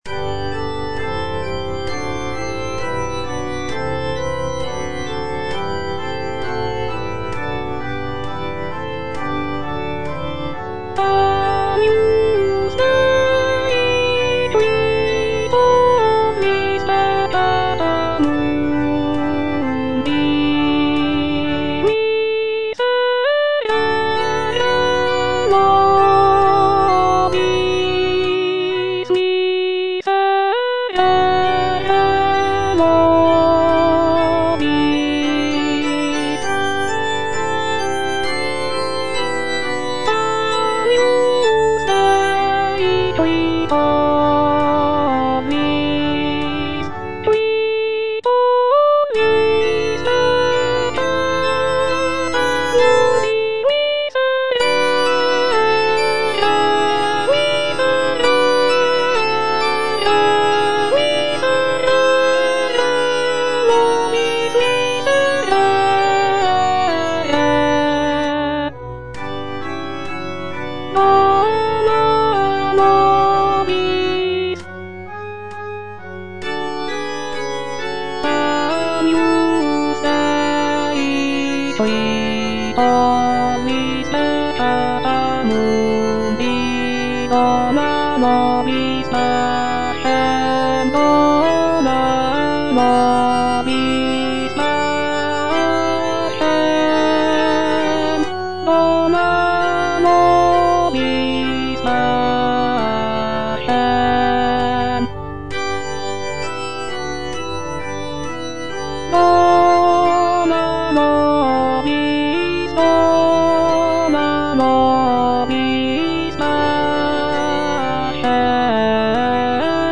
G. FAURÉ, A. MESSAGER - MESSE DES PÊCHEURS DE VILLERVILLE Agnus Dei (alto I) (Voice with metronome) Ads stop: auto-stop